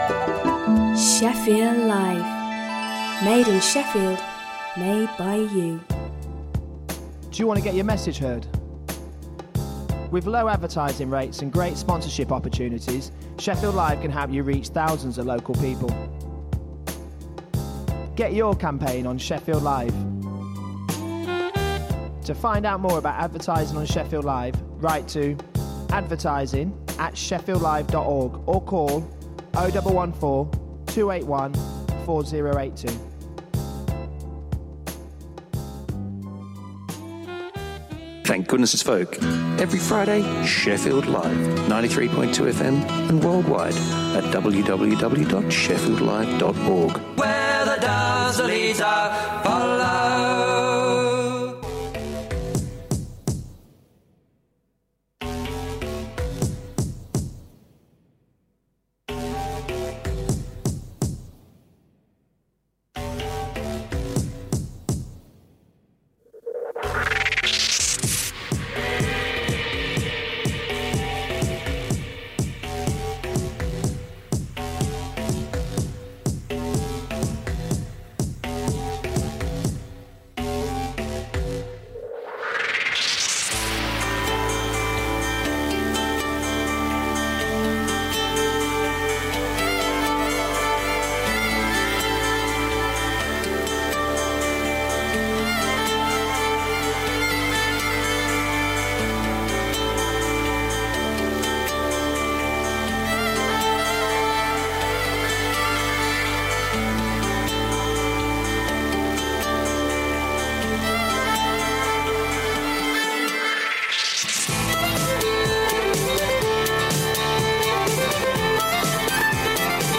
Traditional folk music from the British Isles